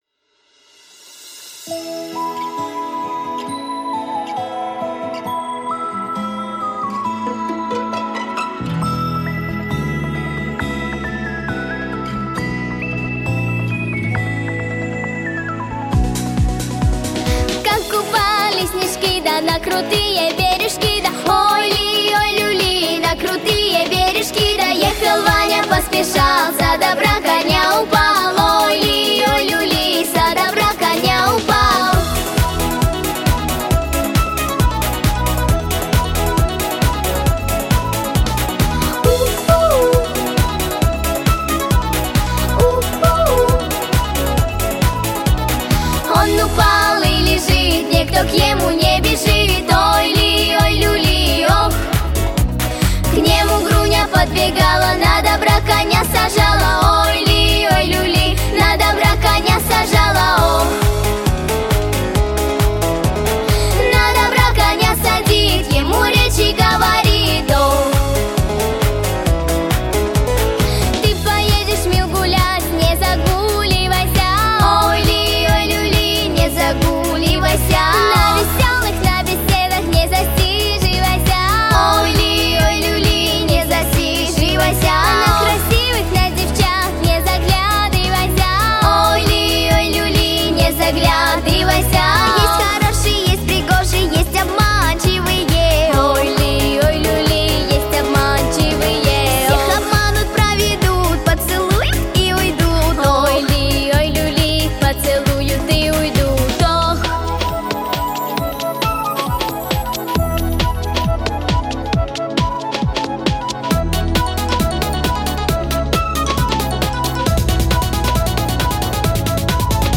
• Качество: Хорошее
• Жанр: Детские песни